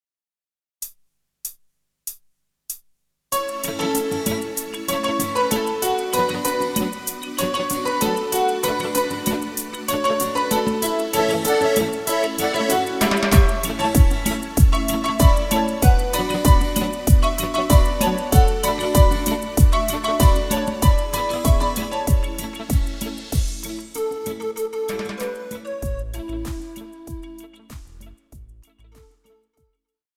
Žánr: Pop
BPM: 96
Key: A
MP3 ukázka s ML